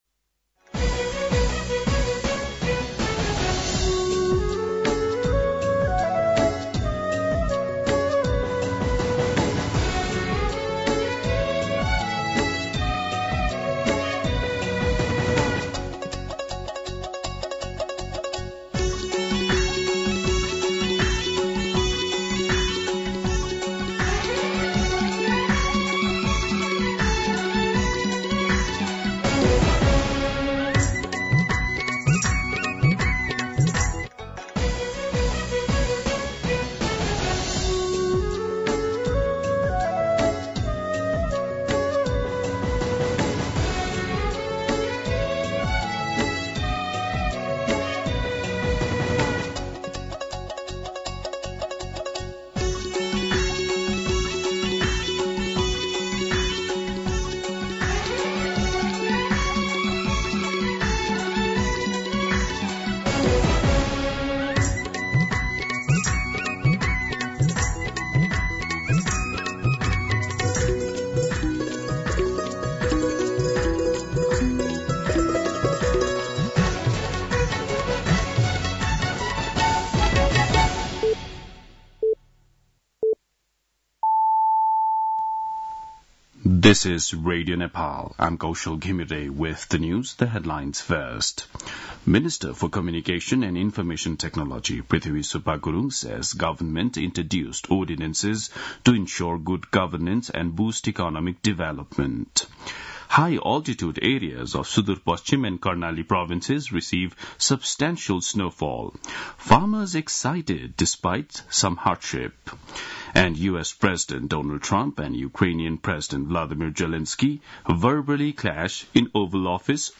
दिउँसो २ बजेको अङ्ग्रेजी समाचार : १८ फागुन , २०८१